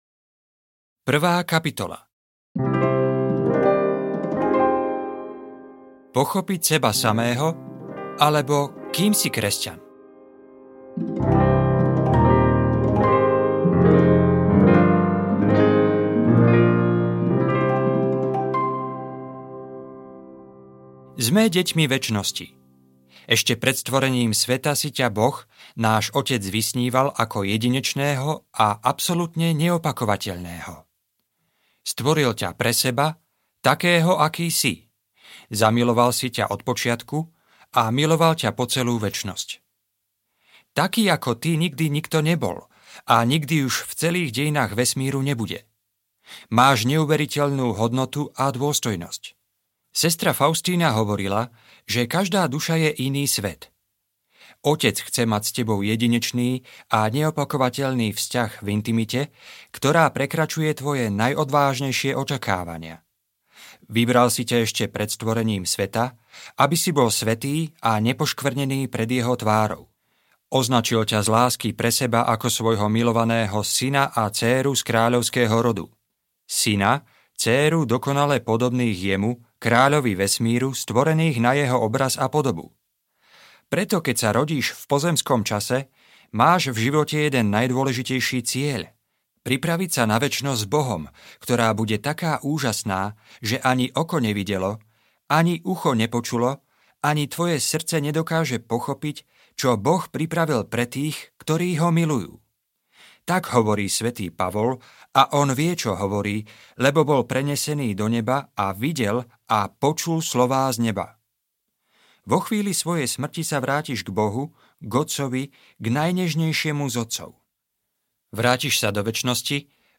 Jeho láska ťa uzdraví audiokniha
jeho-laska-ta-uzdravi-audiokniha